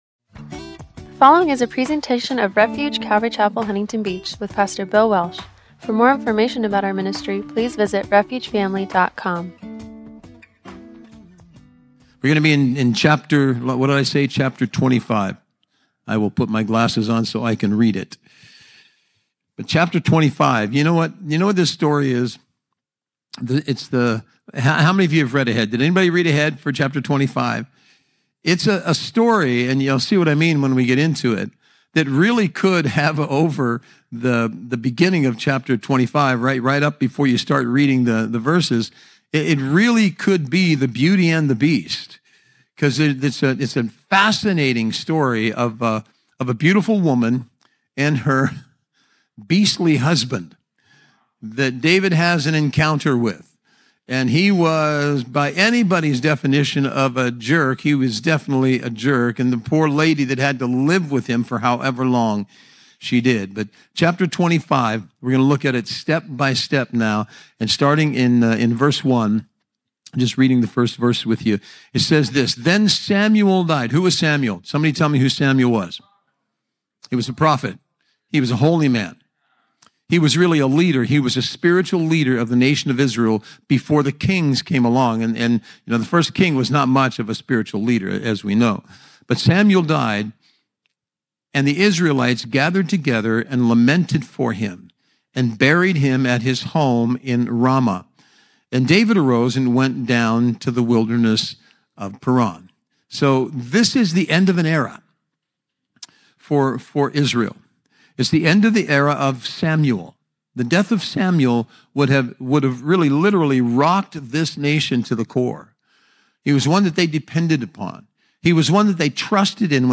“The End of an Era”-1 Samuel 25 – Audio-only Sermon Archive
Service Type: Wednesday Night